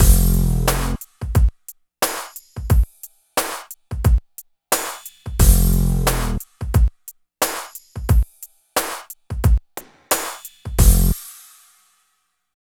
16 LOOP   -L.wav